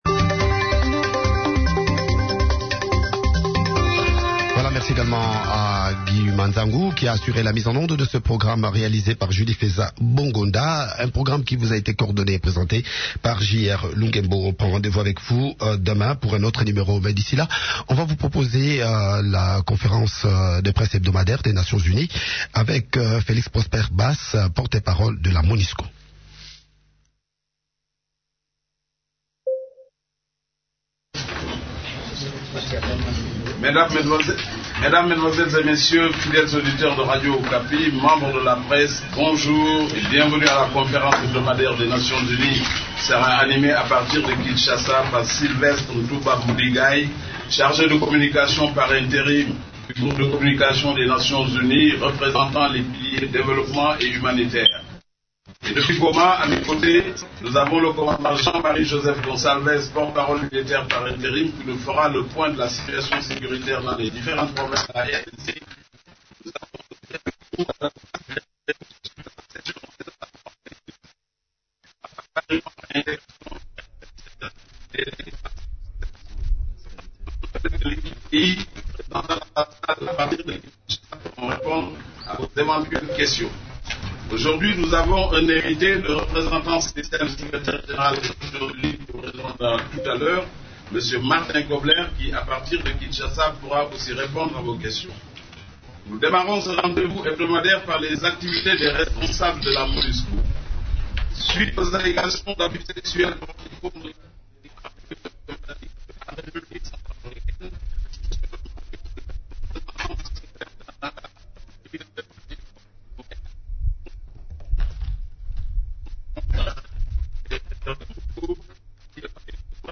Conférence de presse du mercredi 26 août 2015
La conférence hebdomadaire des Nations unies du mercredi 26 août à Kinshasa a essentiellement tourné autour des activités des composantes de la Monusco, les activités menées par les agences et programmes des Nations unies (l’Equipe Pays) et de la situation sécuritaire dans les différentes provinces de la RDC.